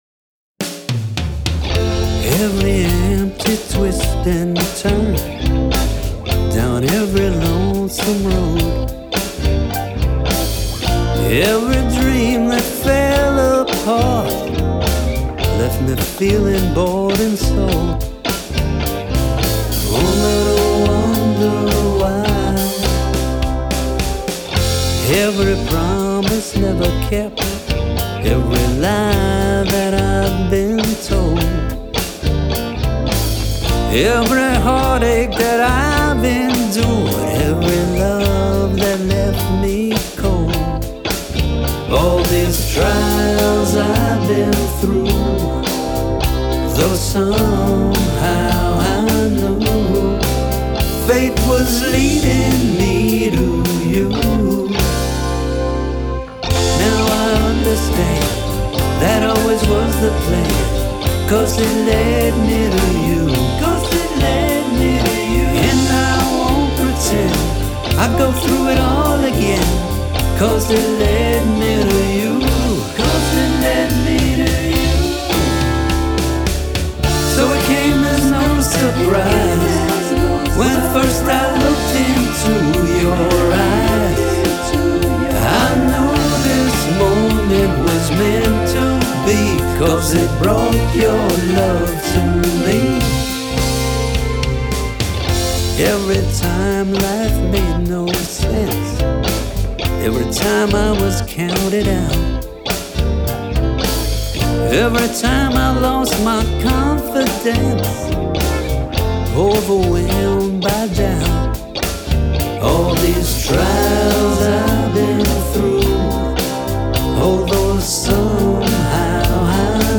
Title: ‘Cause It Led Me To You   Genre: Pop-Rock  Rating
DAW/Computer – Logic Pro X, MacBook Pro; Interface – Focusrite Clarett 4Pre; Monitors – Kali L6; Headphones – Sony MDR-7506, Microphone – AKG C414; Instruments – Fender Strat, Fender P-Bass; Soundware – Toontack EZdrummer 2, Logic Pro Virtual Instrument Collection; Plugins – Various Waves and Logic; Control Surface – PreSonus Faderport 8
The guitar and bass are played live, the drums are created with Toontrack EZdrummer 2 and other sounds are pulled from the Logic Pro Virtual Instrument Collection.
A drum fill starts the song, with the verse starting immediately, followed by what the Beatles called the ‘middle eight’ and many Nashville musicians refer to as ‘the channel’ before a harmony laden chorus.
After the second chorus, the tune modulates up a step into a final repeat chorus, a tag and then out.
The song is well written, the instrument sounds are excellent, and I love hearing a well-organized arrangement like this one.
For example, on this recording, there’s the lead vocal, the harmony vocals supporting the lead vocal and the answer vocals, which are harmonized and sound doubled.